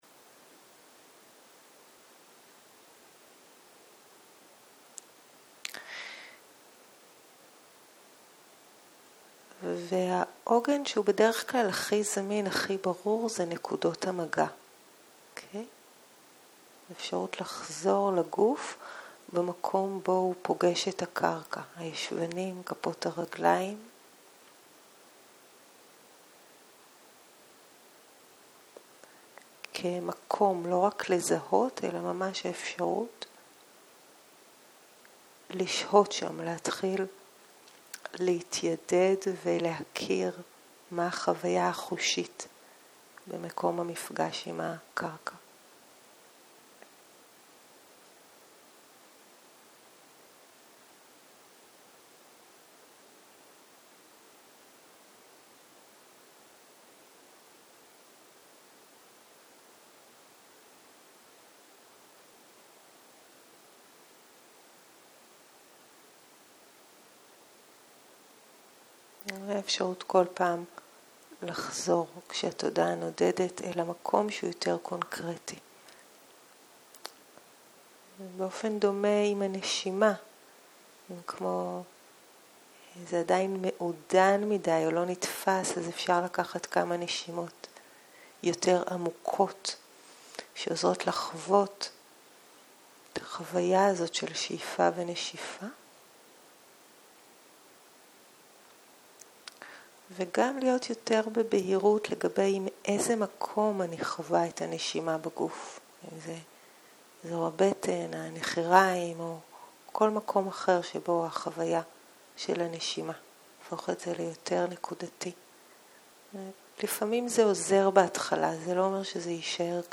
בוקר - מדיטציה מונחית
סוג ההקלטה: מדיטציה מונחית